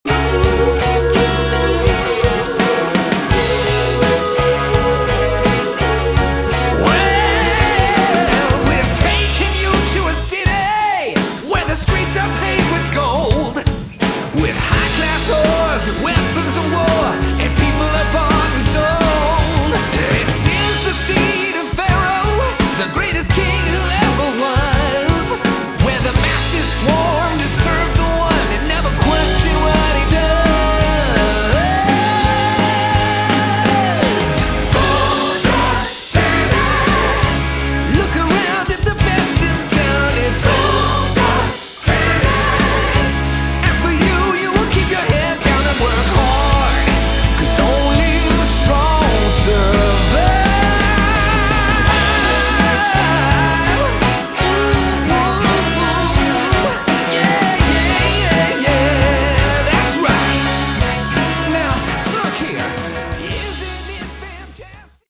Category: Prog Rock
guitars